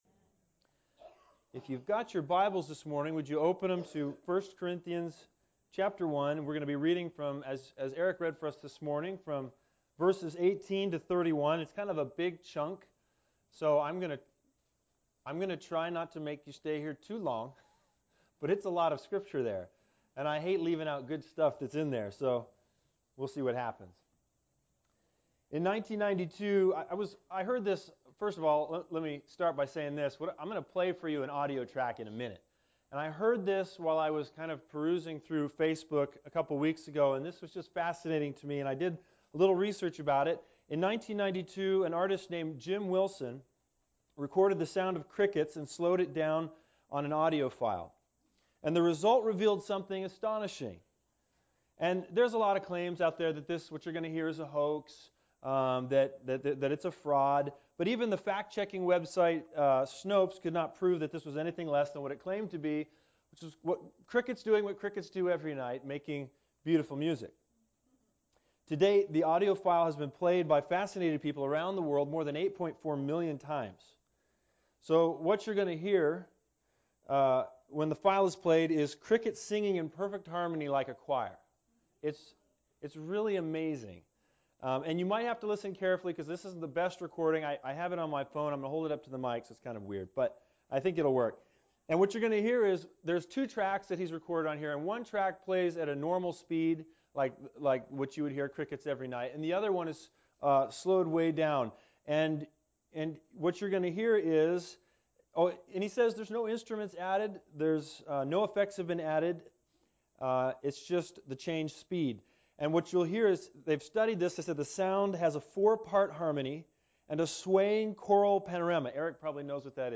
Bible Text: 1 Corinthians 1:18-31 | Preacher